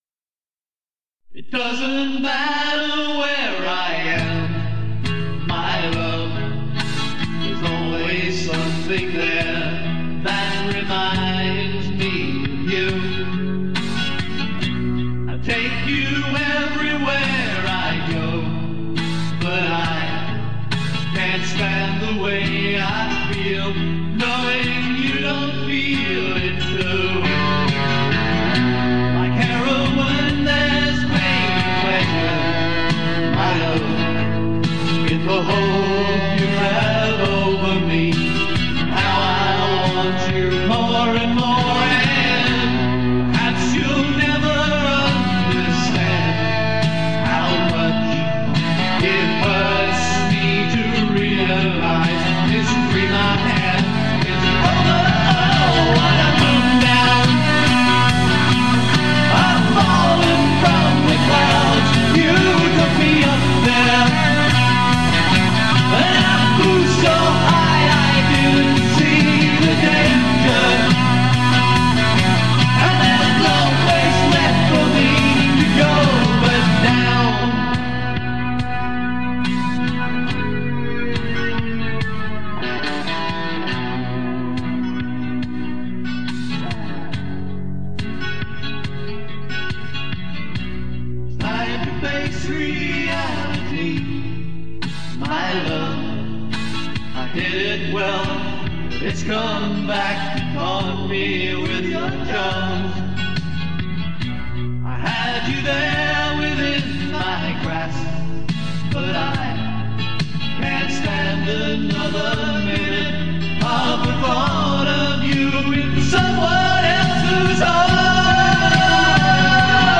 Recorded in Everton Park, Brisbane.